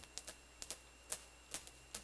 Southern Resident Echolocation Click
The four OVAL array hydrophone locations are noted with small circles.
The vocalizing orca is at the location and depth shown by the straight line from our local reference point.
Clicks.wav